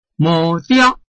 臺灣客語拼音學習網-客語聽讀拼-饒平腔-入聲韻
拼音查詢：【饒平腔】diog ~請點選不同聲調拼音聽聽看!(例字漢字部分屬參考性質)